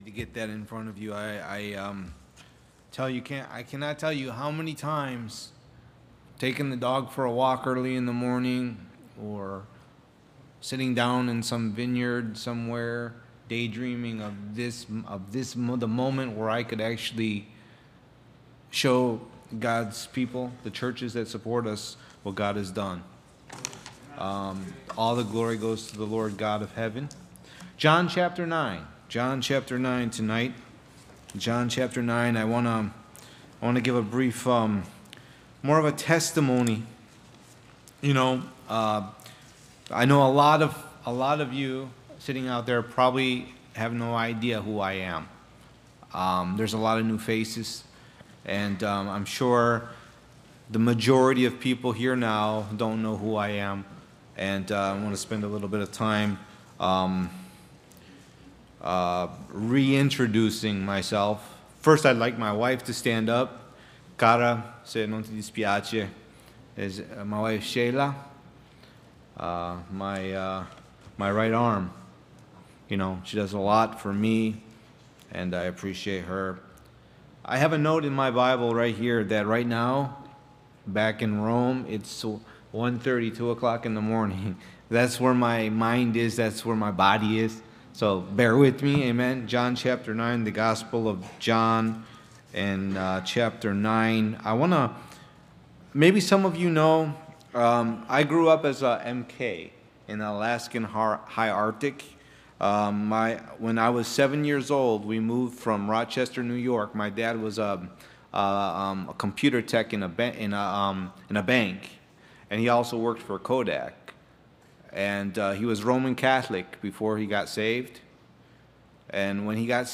Service Type: Sunday Evening, Sunday Morning